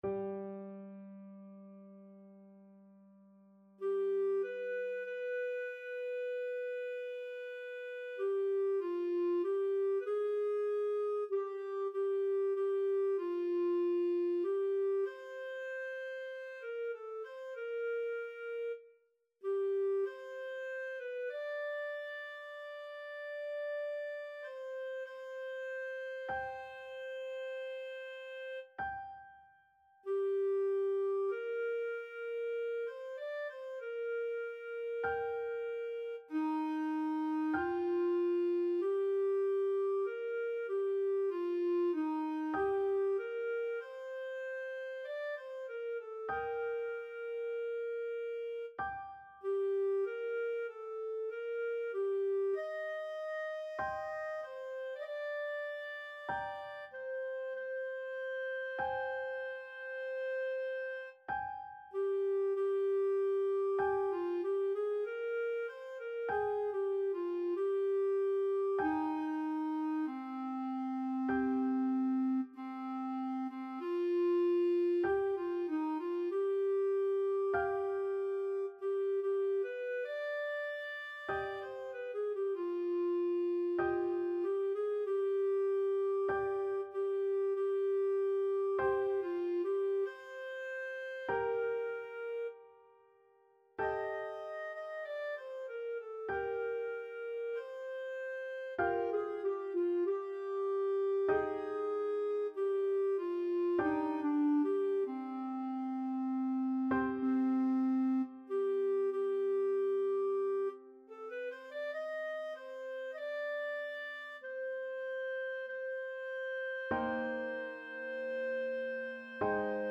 4/4 (View more 4/4 Music)
Lent =48
Classical (View more Classical Clarinet Music)